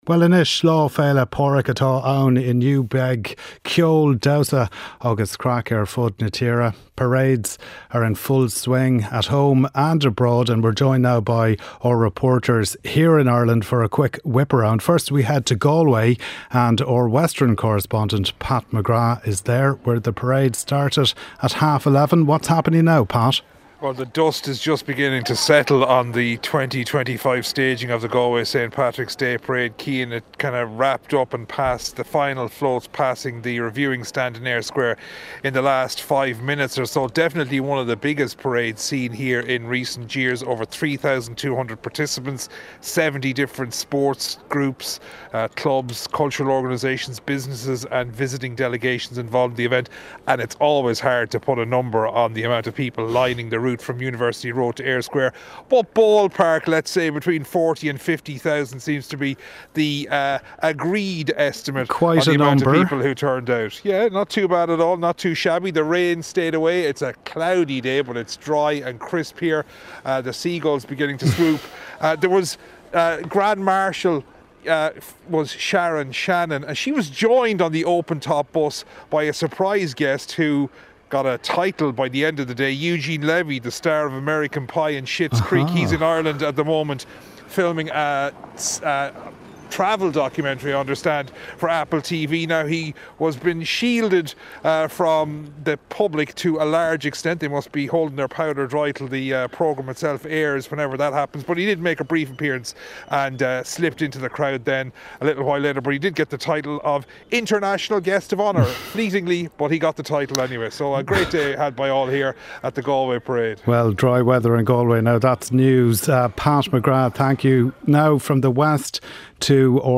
8am News Bulletin - 17.03.2025